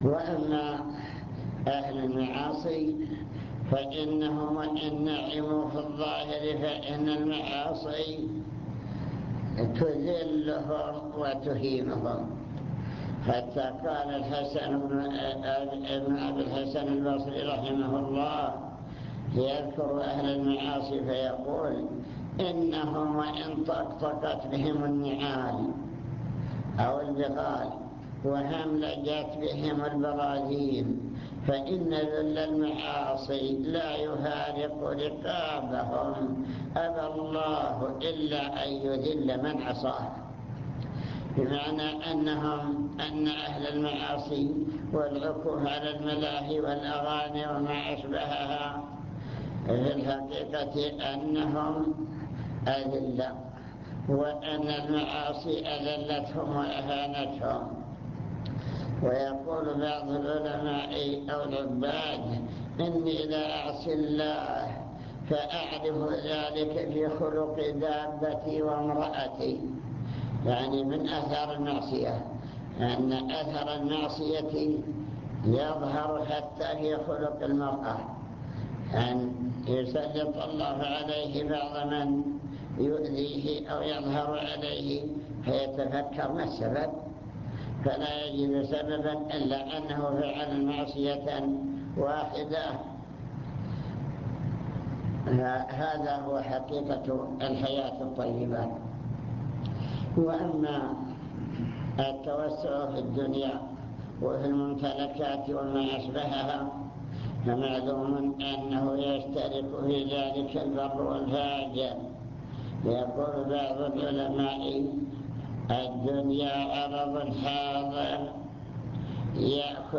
المكتبة الصوتية  تسجيلات - محاضرات ودروس  محاضرة القاعة